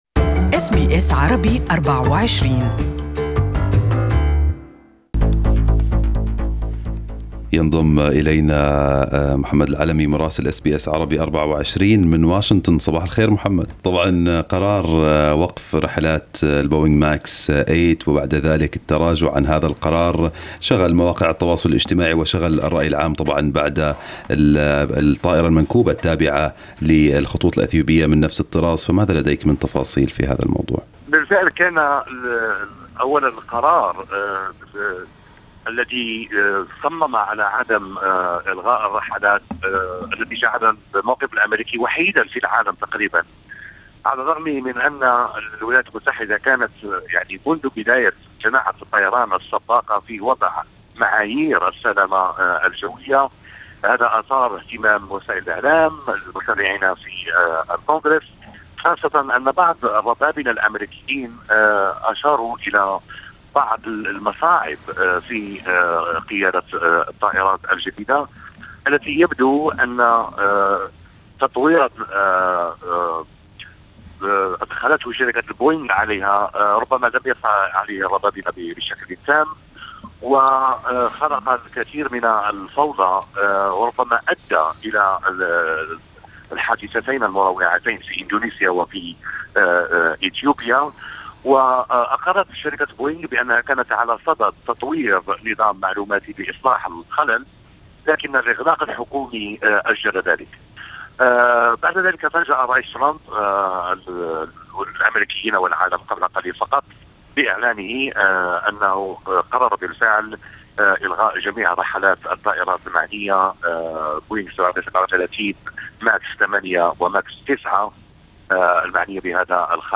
Listen to the full report from Washington in Arabic above